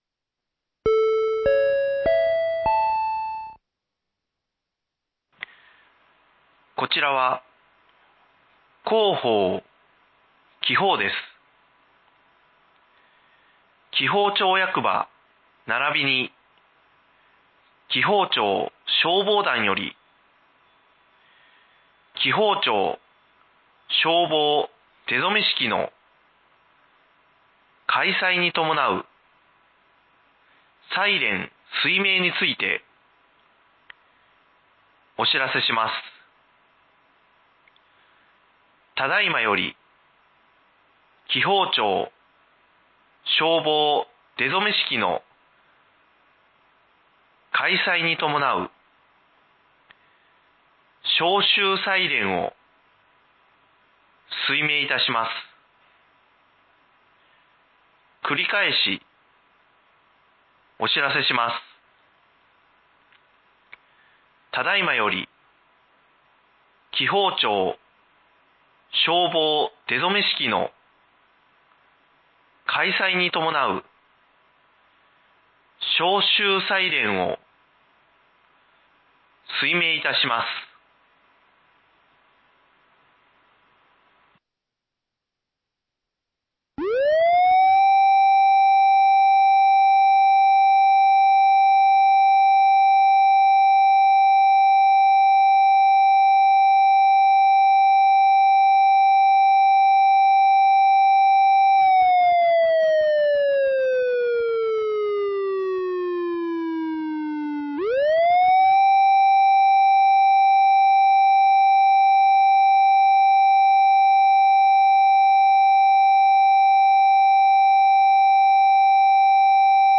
出初式サイレン吹鳴 | 紀宝町防災メール配信サービス
消防出初式の開催に伴う召集サイレンを吹鳴いたします。